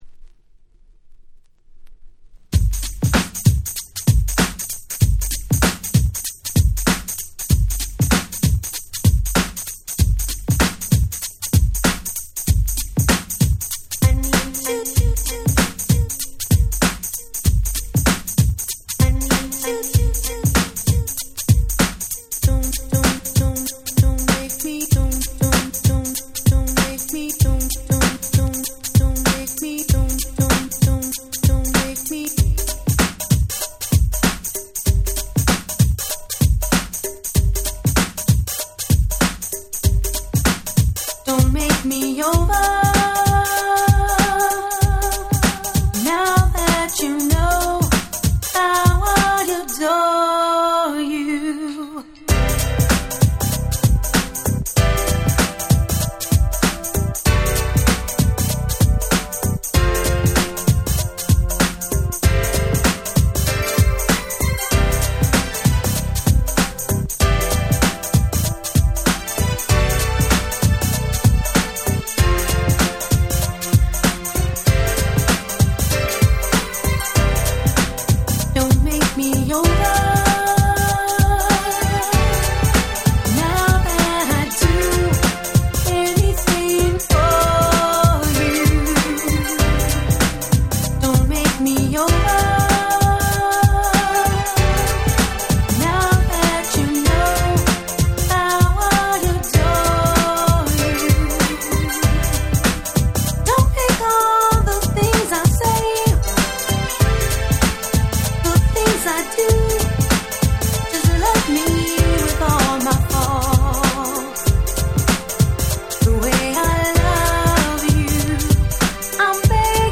89' Super Hit R&B !!!
Ground Beat Classic !!